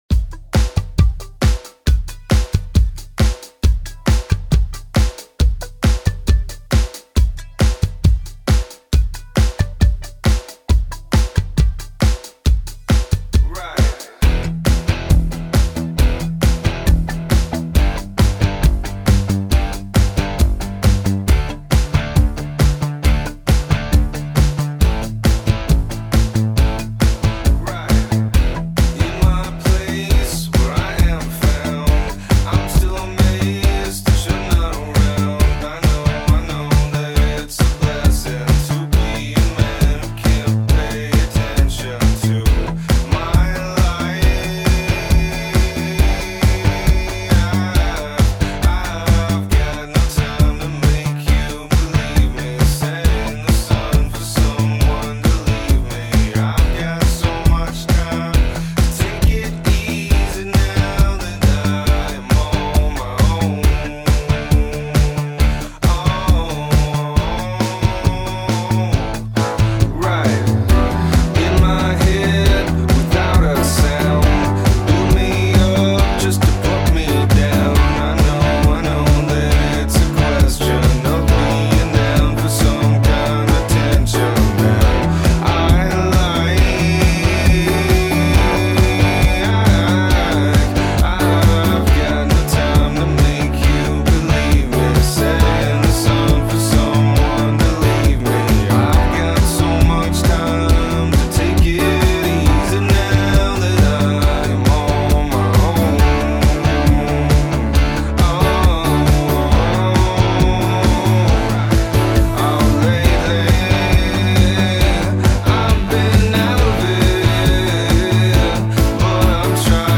shrieking vocals